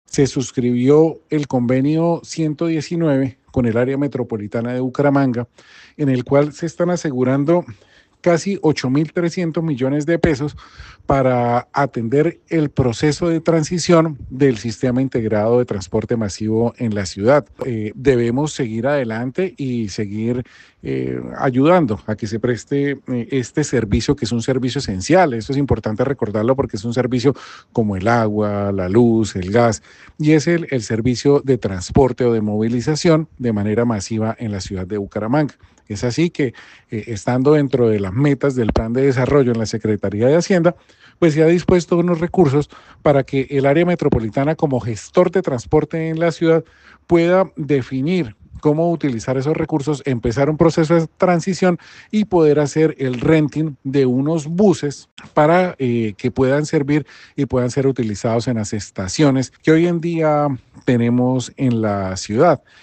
Reynaldo D’ Silva, Secretario de Hacienda de Bucaramanga